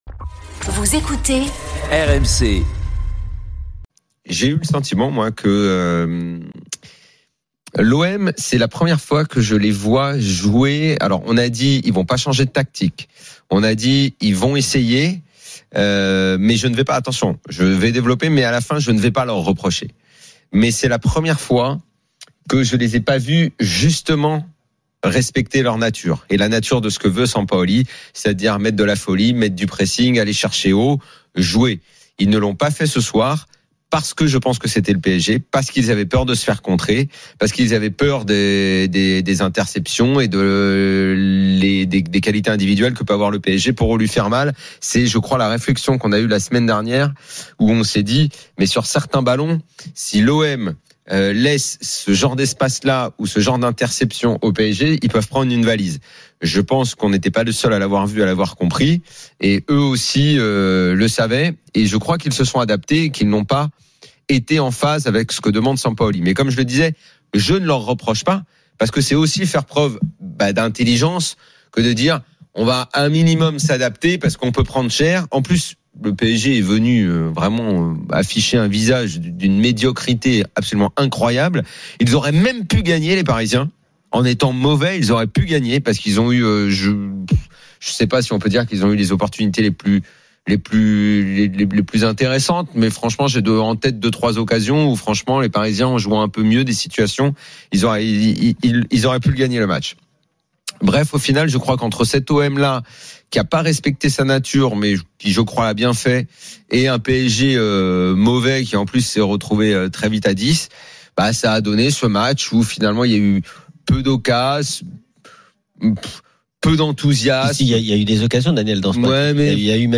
Chaque jour, écoutez le Best-of de l'Afterfoot, sur RMC la radio du Sport !
les conférences de presse d'après-match et les débats animés entre supporters, experts de l'After et auditeurs. RMC est une radio généraliste, essentiellement axée sur l'actualité et sur l'interactivité avec les auditeurs, dans un format 100% parlé, inédit en France.